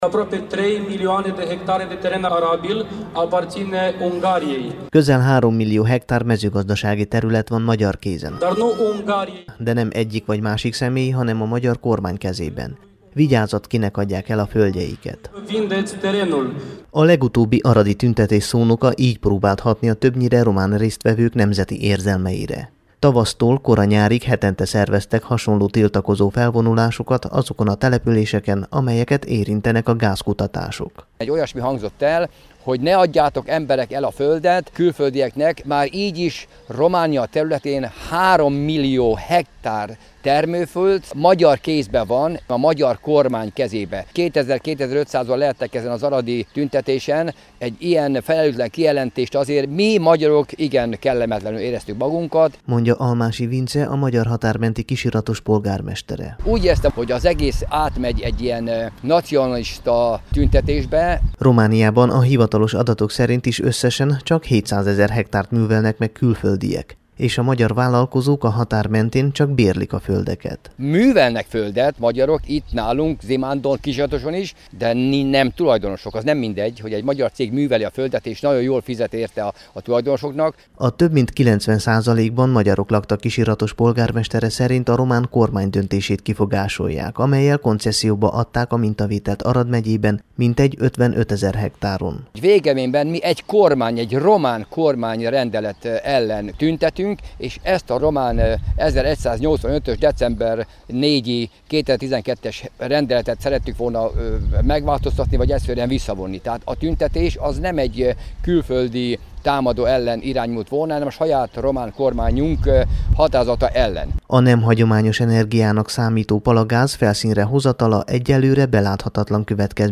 Magyarellenes_palagaztuntetes_radio.mp3